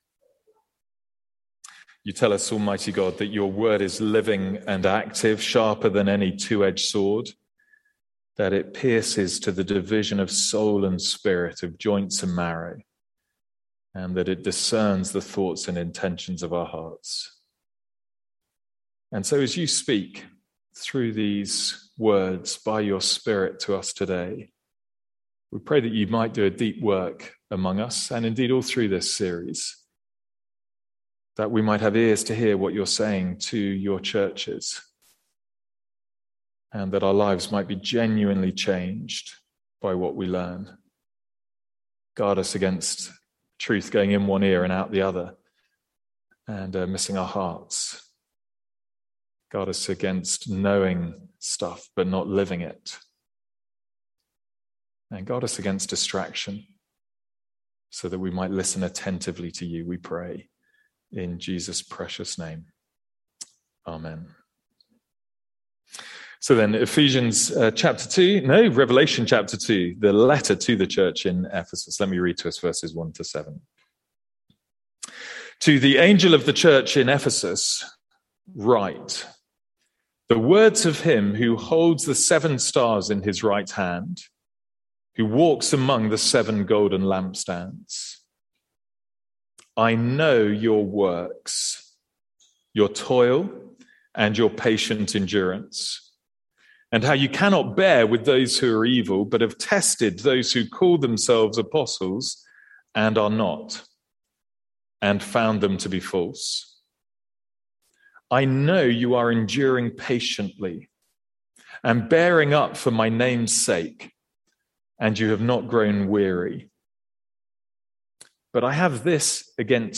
Sermons | St Andrews Free Church
From our evening series in Revelation.